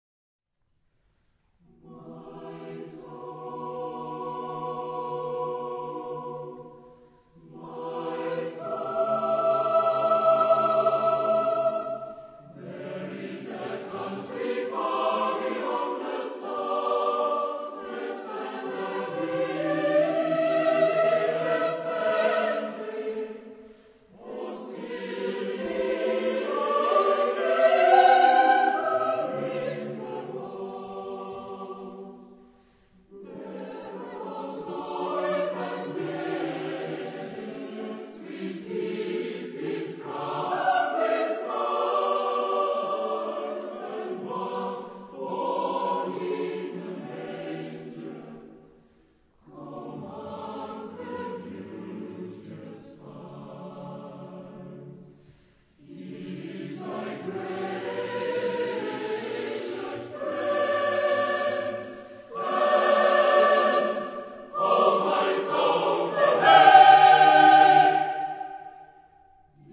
Genre-Style-Forme : Sacré ; Romantique ; Anthem ; Motet ; Hymne (sacré)
Caractère de la pièce : lent
Type de choeur : SATB  (4 voix mixtes )
Tonalité : sol majeur
Réf. discographique : Internationaler Kammerchor Wettbewerb Marktoberdorf